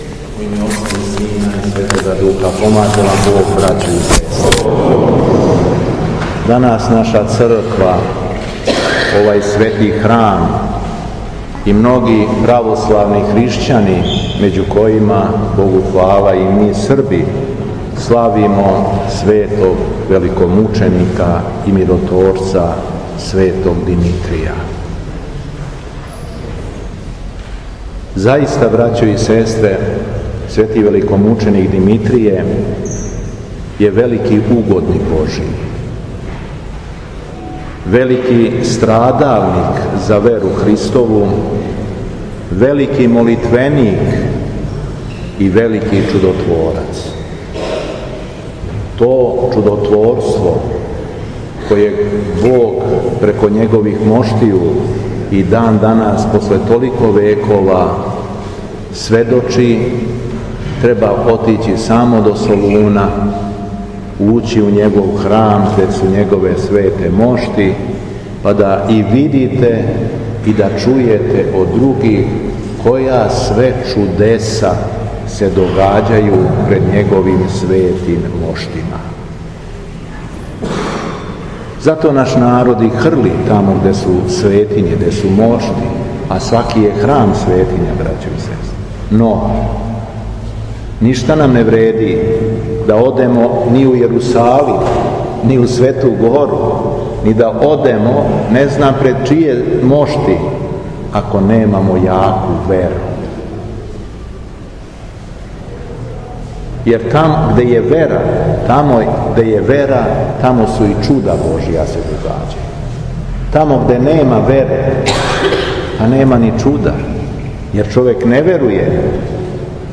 У петак, 8. новембра 2019. године, када Црква прославља светог великомученика Димитрија, Његово Преосвештенство Епископ шумадијски Господин Јован, служио је архијерејску Литургију у Лазаревцу, поводом храмовне славе.
Беседа Епископа шумадијског Г. Јована